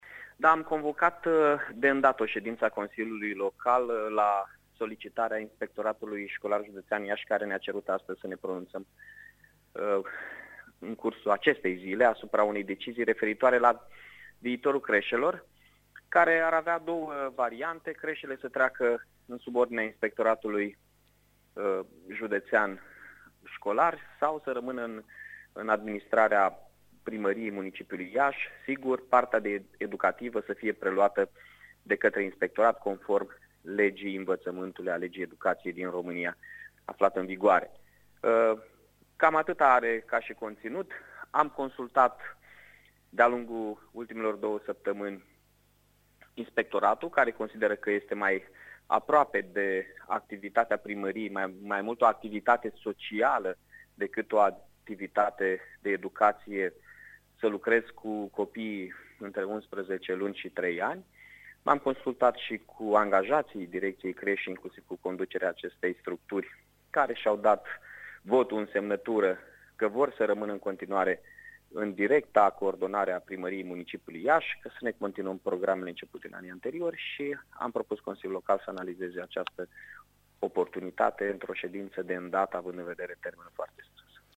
Vă prezentăm și declarațiile primarului Mihai Chirica.